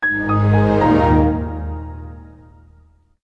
Windows Xp Shutdown Meme - Bouton d'effet sonore